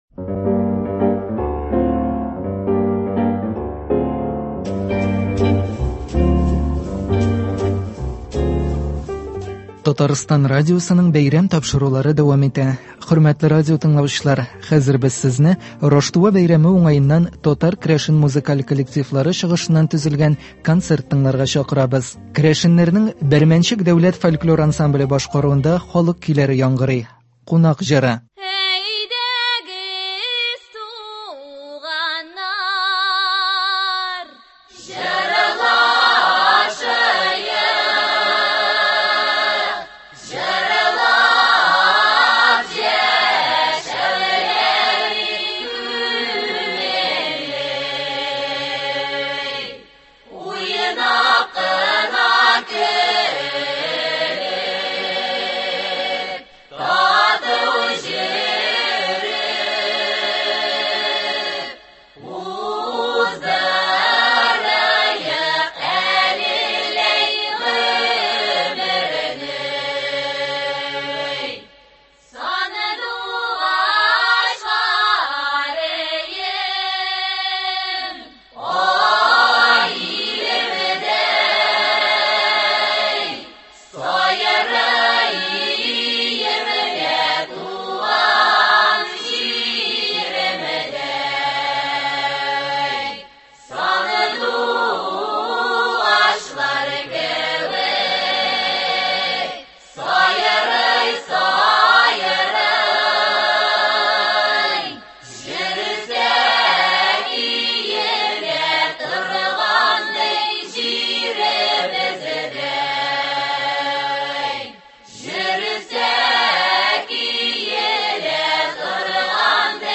Республикабызның татар-керәшен музыкаль коллективлары концерты.